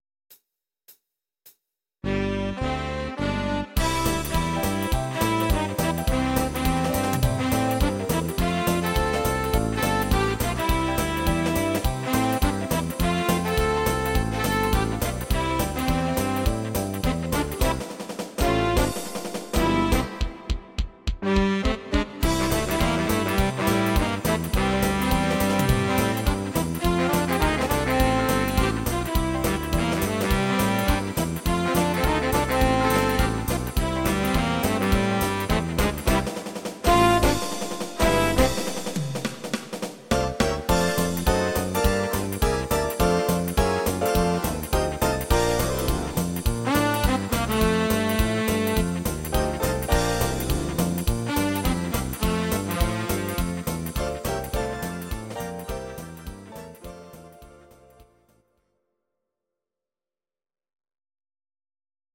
Please note: no vocals and no karaoke included.
Instr. Big Band